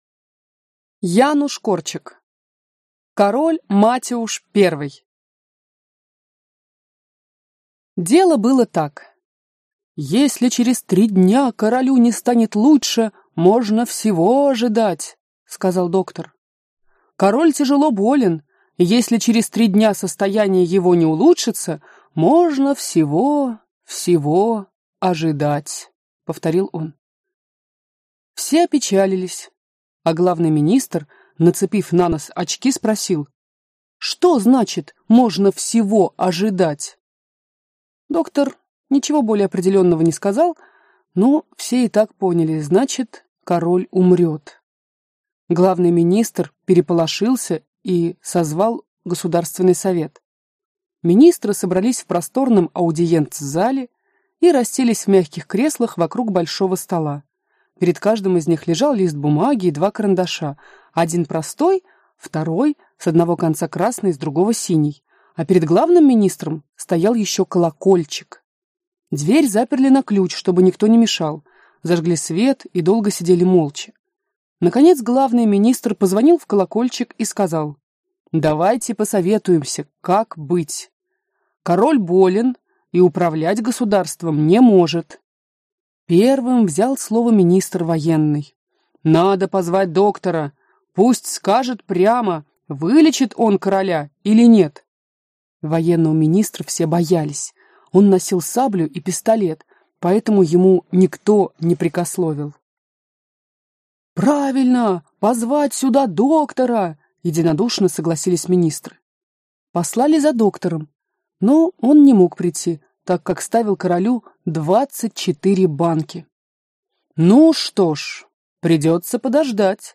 Аудиокнига Король Матиуш Первый - купить, скачать и слушать онлайн | КнигоПоиск